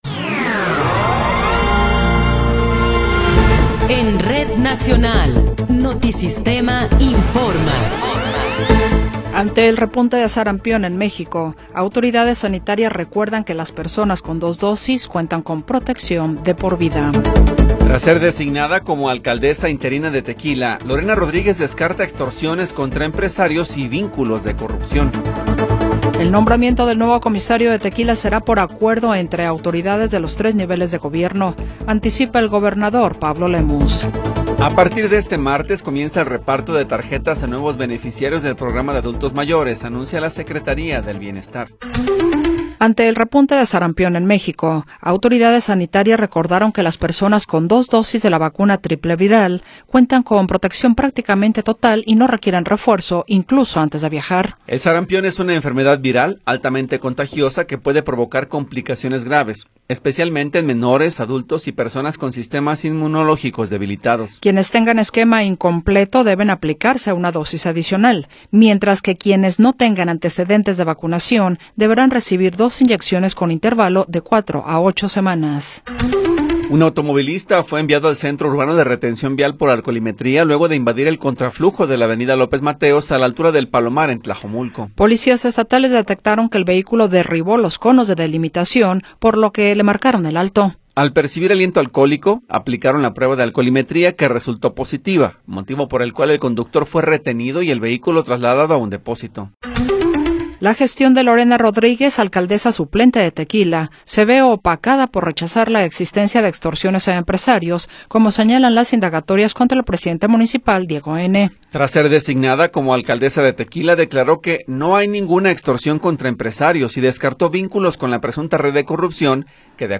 Noticiero 14 hrs. – 9 de Febrero de 2026